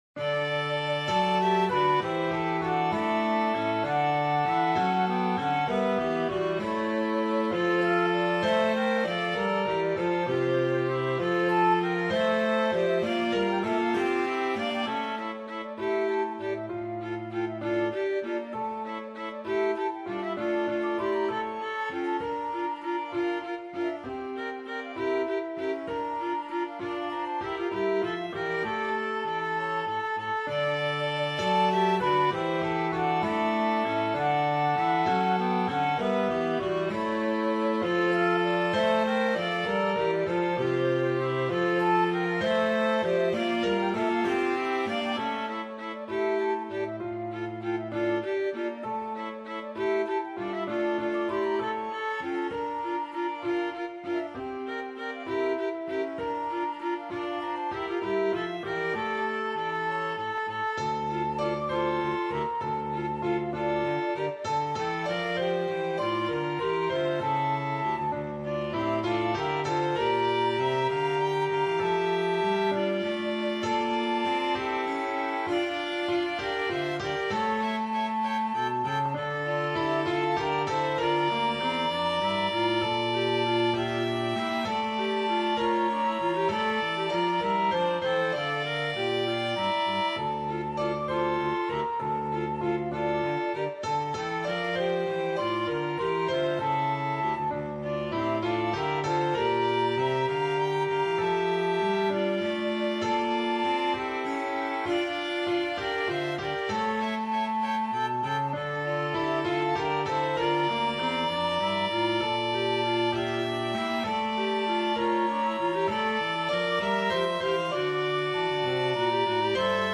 8.-Lluna-de-la-ciutat-Instrumental.mp3